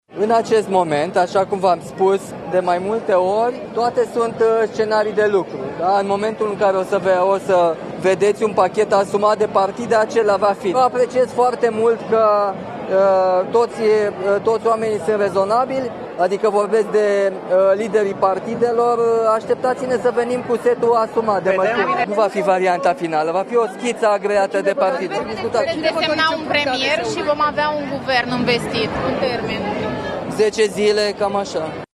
„Nu va fi varianta finală a pachetului de măsuri fiscale”, a spus președintele Nicușor Dan, prezent ieri la Sala Polivalentă, la meciul de retragere al Cristinei Neagu.